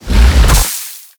Sfx_creature_snowstalker_standup_end_01.ogg